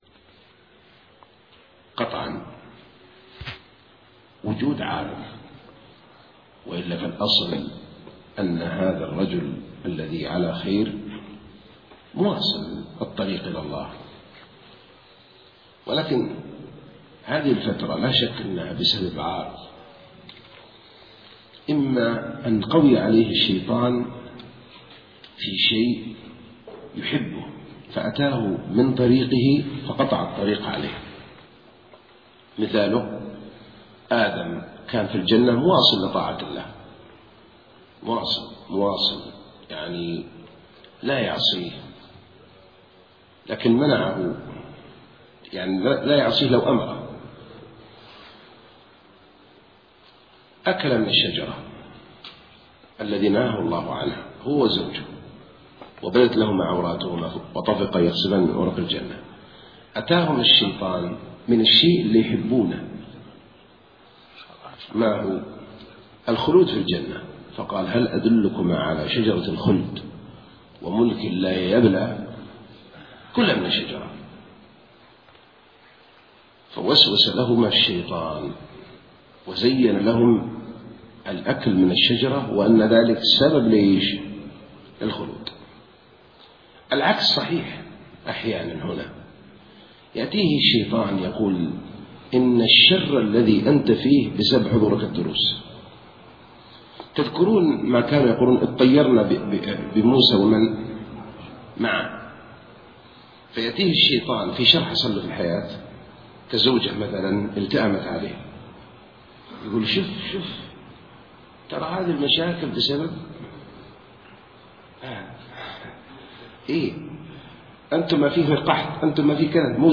محاضرات